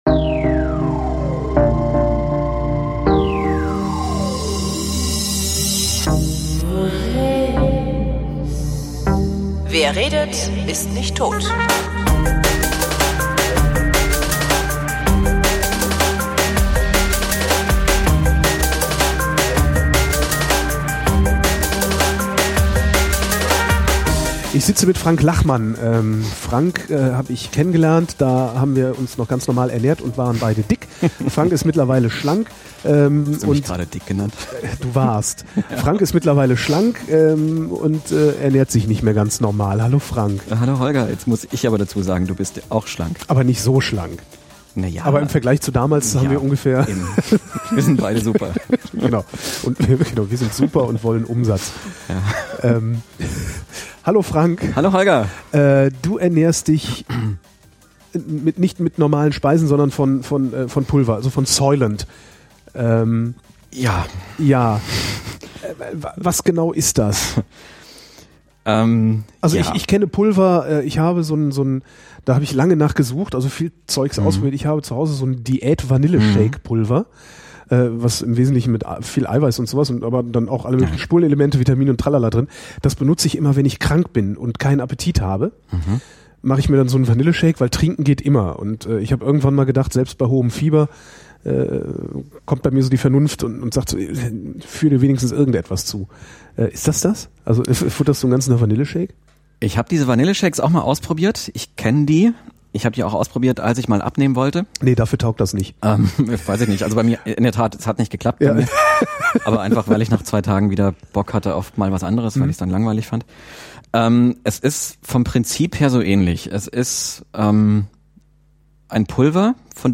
Achtung: In der ersten Version der Sendung ist beim Mischen etwas schiefgelaufen, so dass das Intro mittendrin nochmal auftaucht.